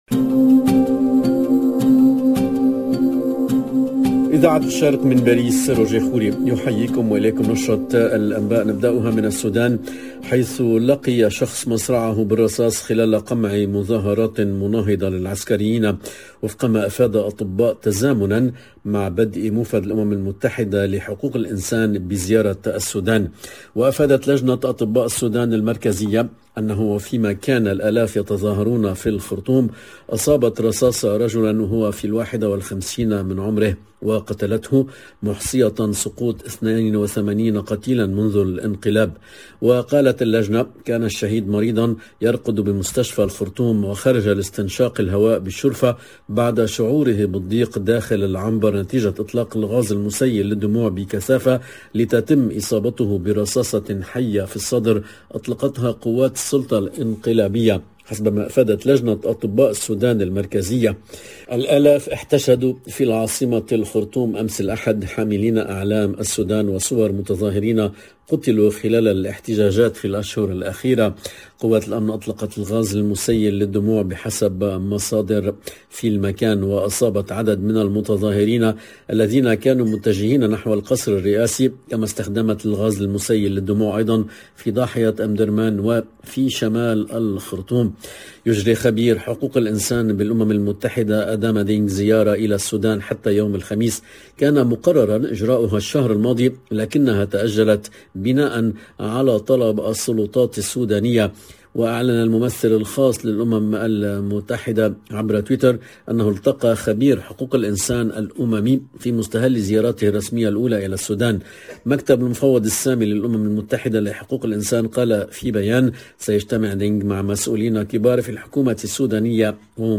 LE JOURNAL EN LANGUE ARABE DE MIDI 30 DU 21/02/22